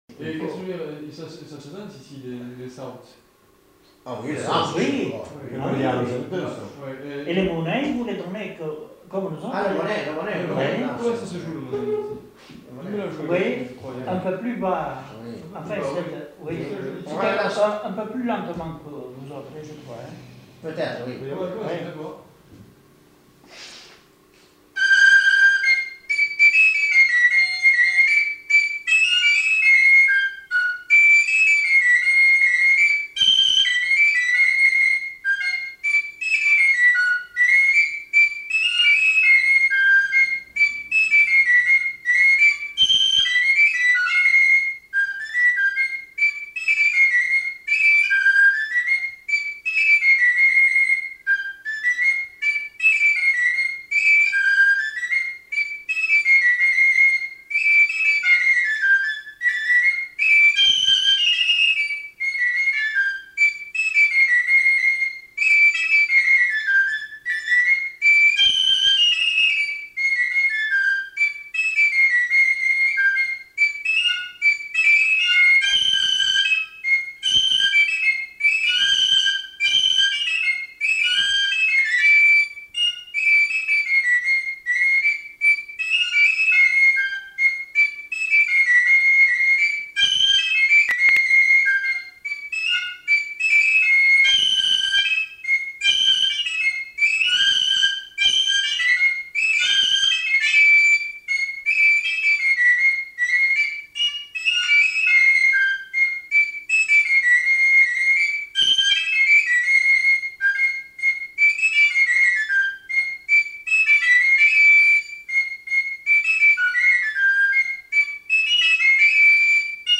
Aire culturelle : Pays Basque
Lieu : Trois-Villes
Genre : morceau instrumental
Instrument de musique : txistu
Notes consultables : L'interprète apporte des précisions sur ce saut en début de séquence.